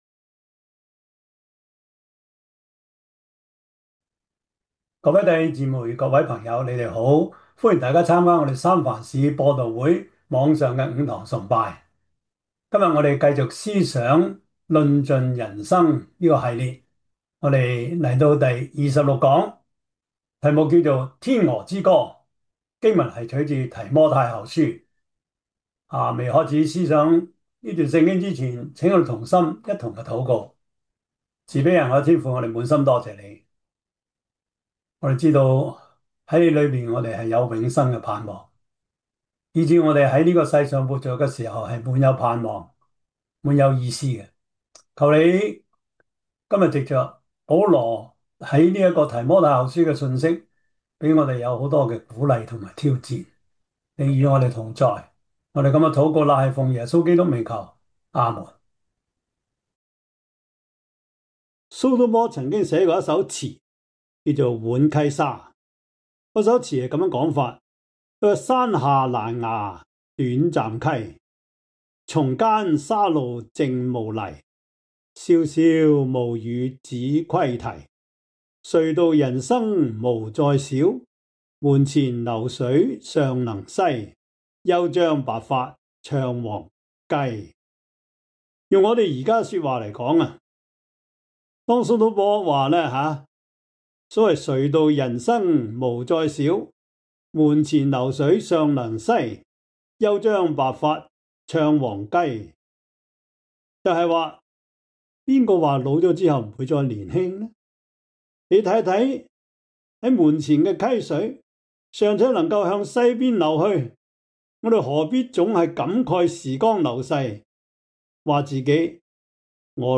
2026 主日崇拜 Passage: 提摩太後書 1:7-8,2:3-6,3:1-7,3:16-17,4:1-8 Service Type: 主日崇拜